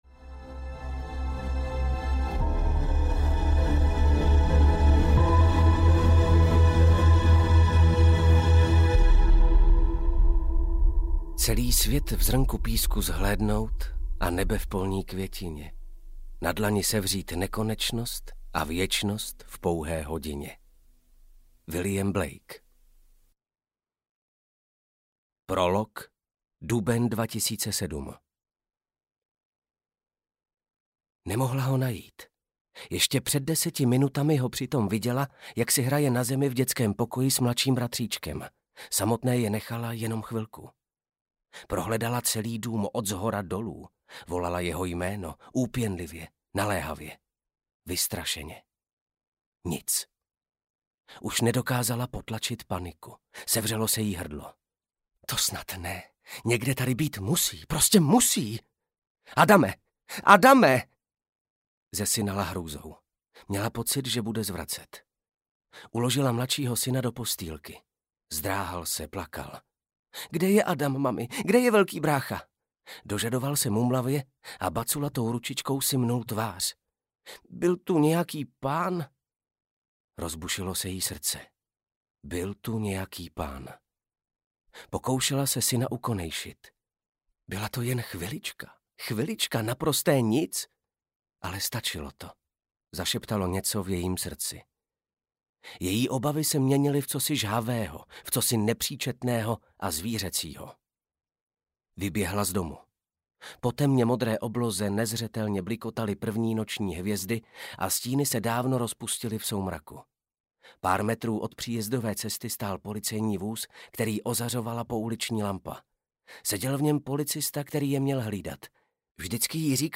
Ukázka z knihy
Hudba Karpof Brothers | Natočeno ve studiu KARPOFON (AudioStory)
pamatuj-na-smrt-audiokniha